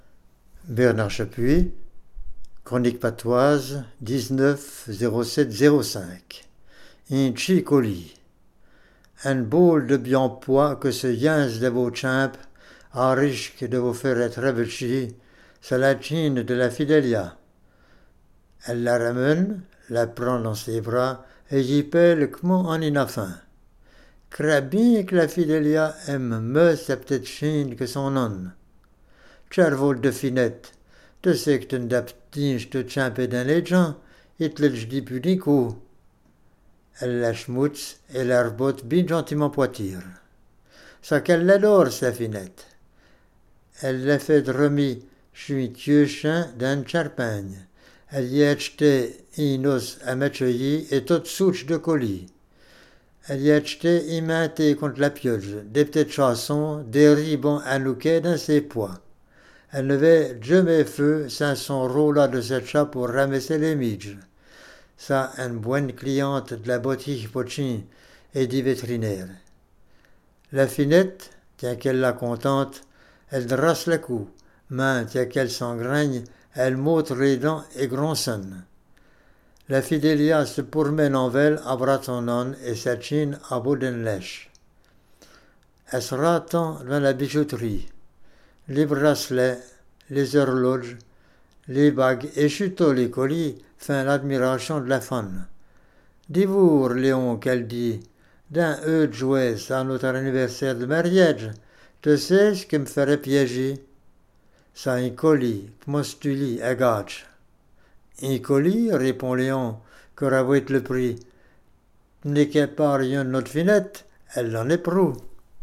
Djasans Patois Jurassien
Ecouter la chronique lue par